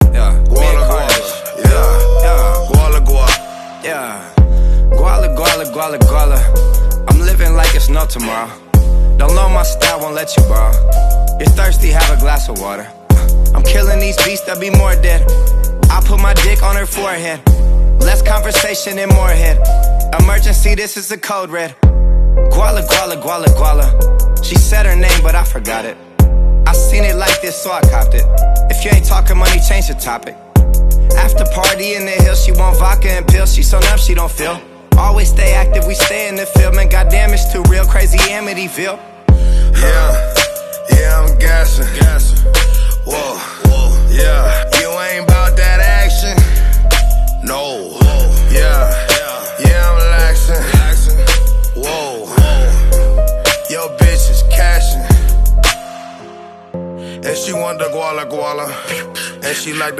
3x 12s With 5000w Amplifier Sound Effects Free Download